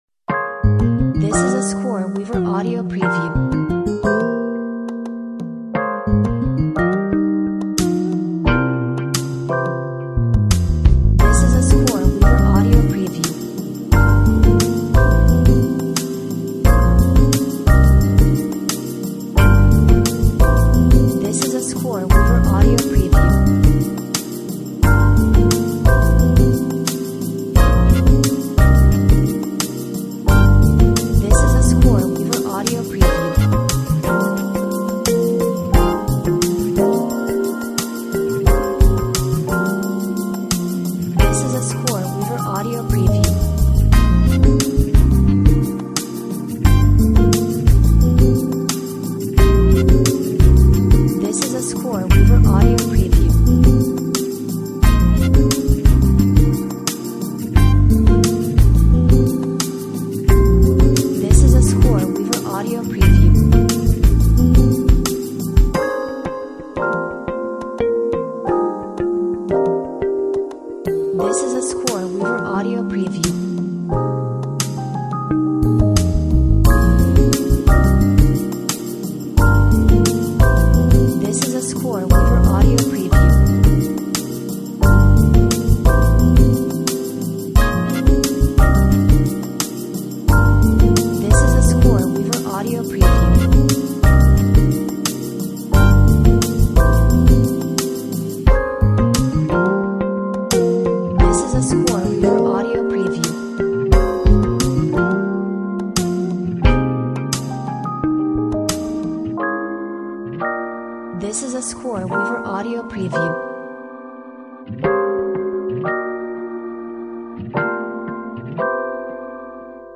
Warm and gentle Rnb / Soul instrumental background music.